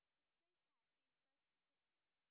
sp16_white_snr0.wav